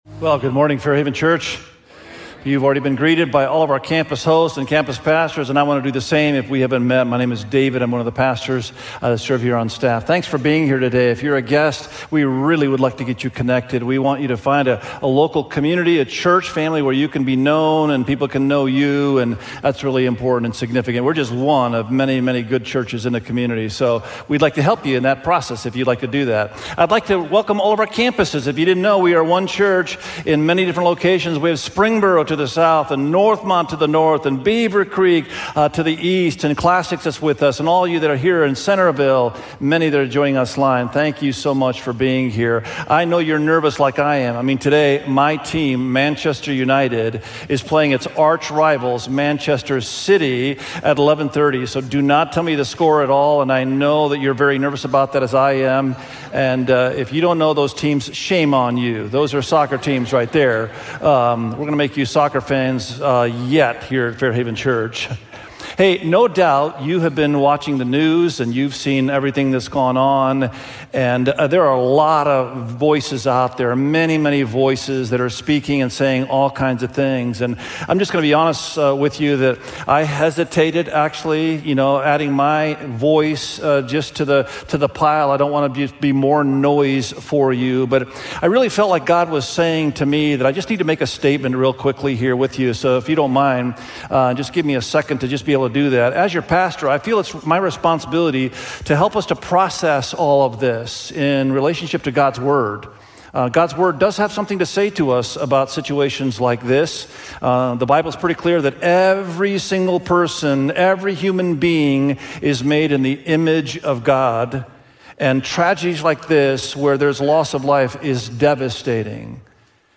Wealth-Is-Not-the-Problem_SERMON.mp3